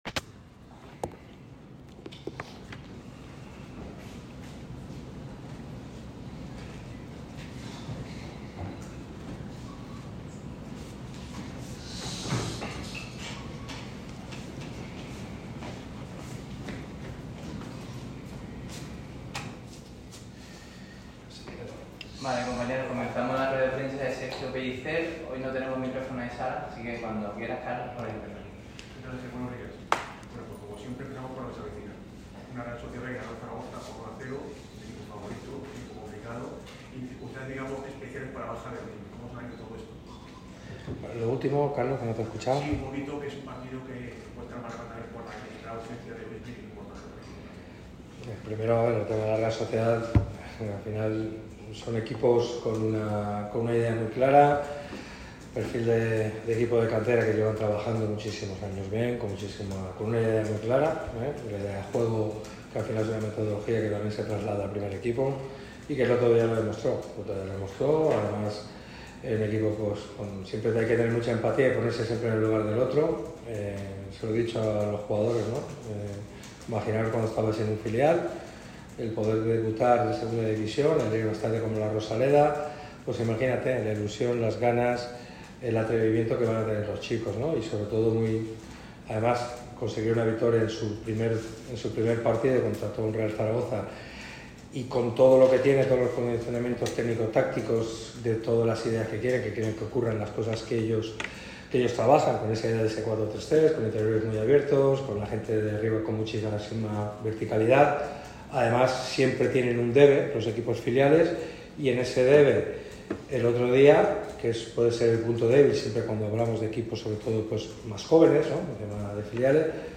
ha atendido a los medios en rueda de prensa con motivo de la previa de la segunda jornada de LaLiga Hypermotion. Los blanquiazules reciben en La Rosaleda a la Real Sociedad B el próximo domingo a las 21:30 horas después del empate de la semana pasada ante la SD Eibar en el debut liguero.